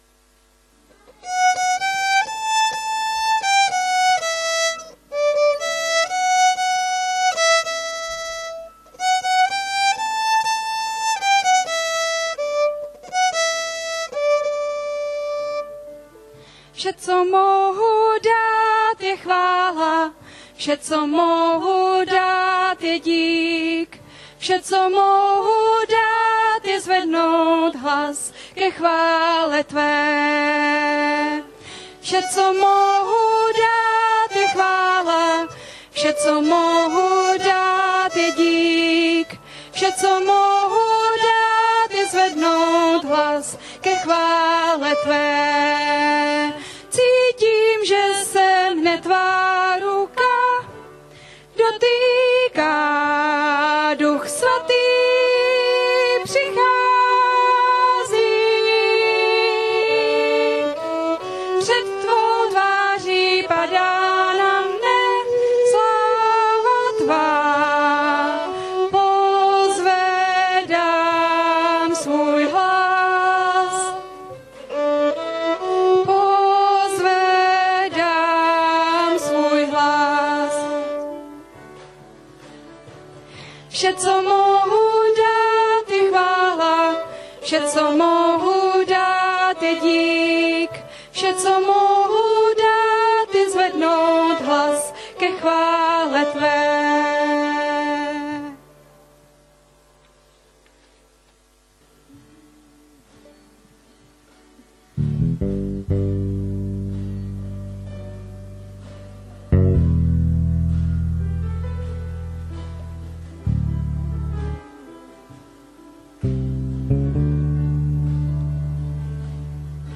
Tesalonickým 5:18 7 října 2018 Řečník: ---více řečníků--- Kategorie: Nedělní bohoslužby Husinec přehrát / pozastavit Váš prohlížeč nepodporuje přehrávání audio souborů. stáhnout mp3